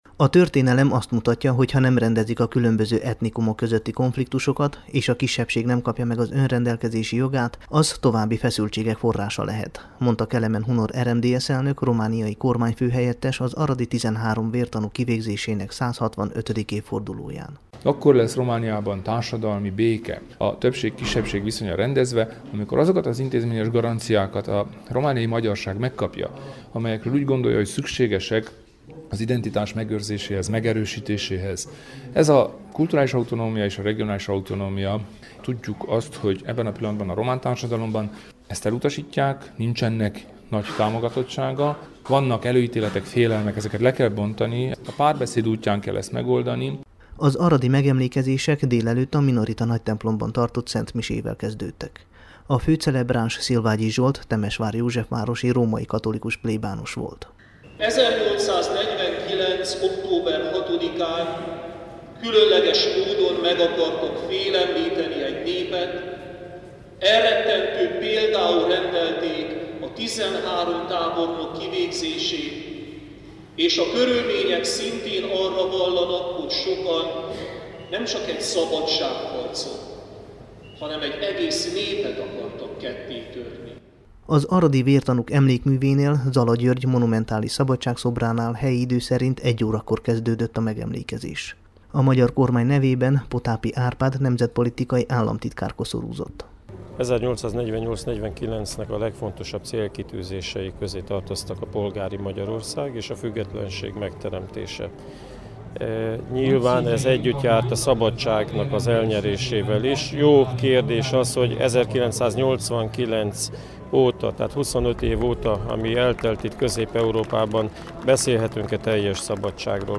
összefoglalója a Kossuth Rádió Határok nélkül című műsora számára készült.